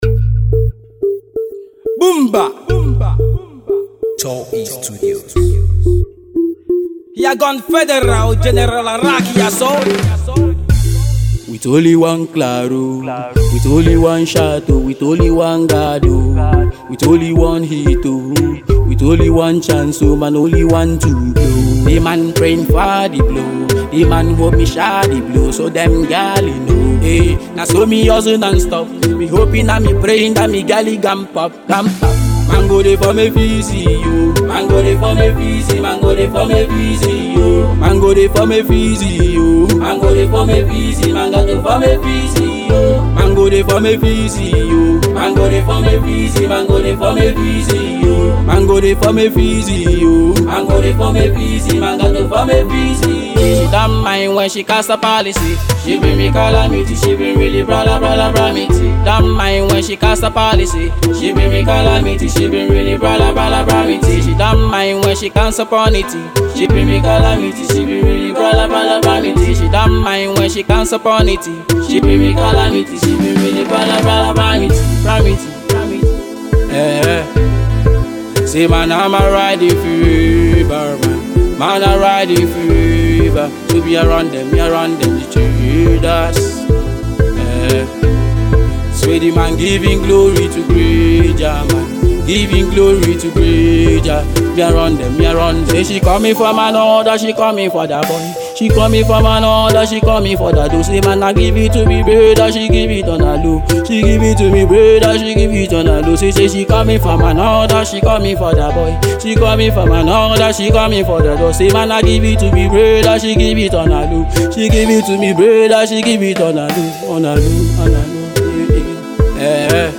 Afro Ragga/Dancehall
Melodious Chune